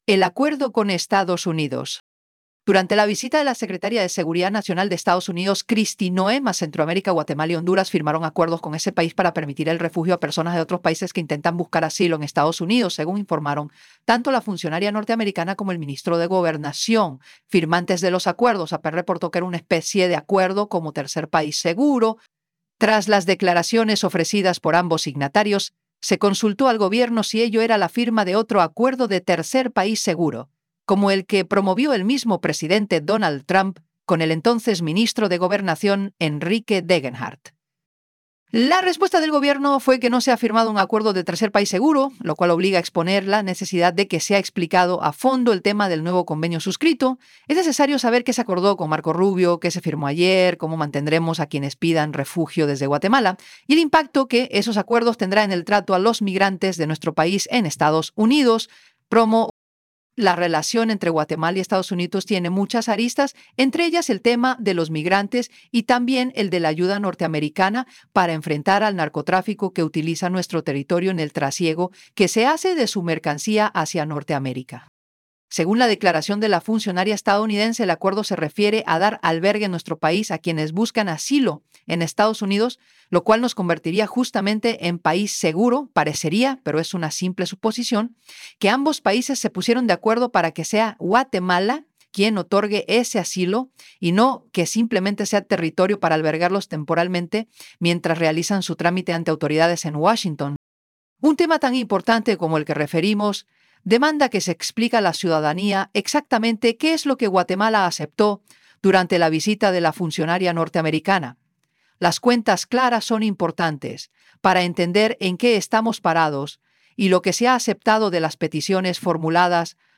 Editorial_-PlayAI_El_acuerdo_con_Estados_Unidos.wav